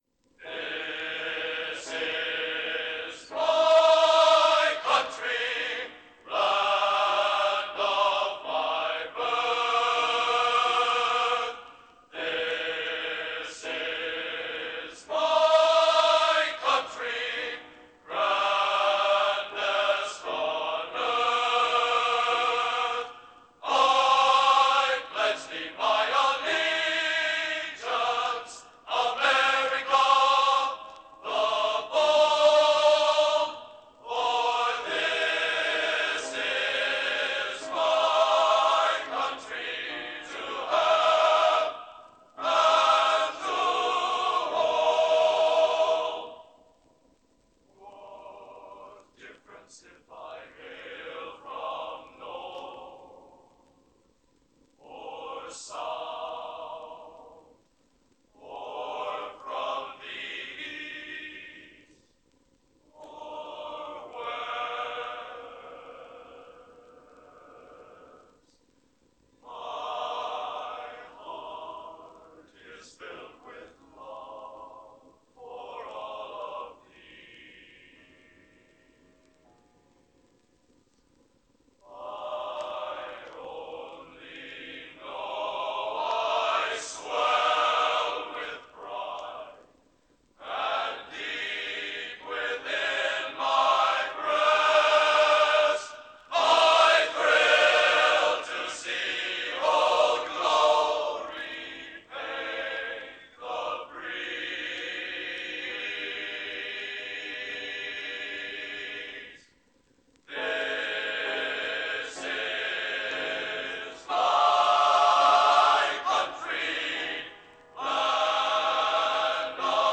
Genre: Patriotic | Type: Studio Recording